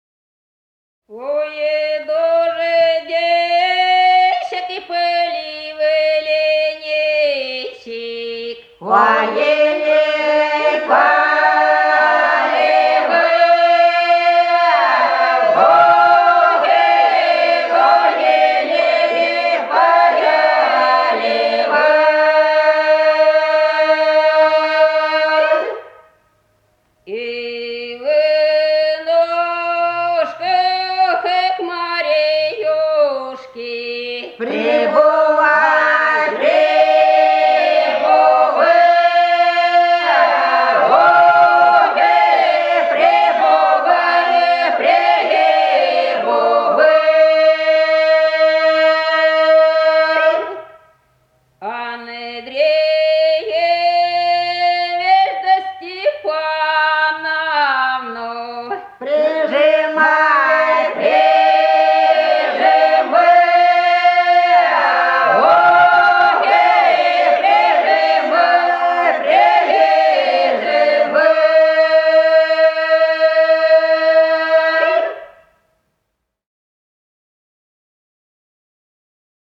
Голоса уходящего века (село Фощеватово) Ой, дождичек, поливальничек (летняя трудовая)